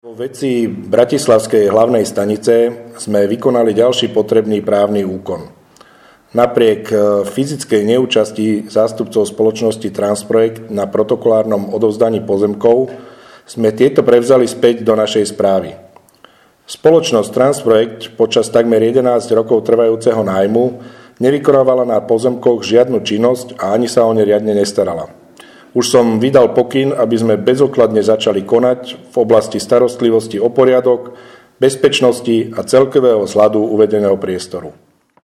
Nahrávka tlačovej správy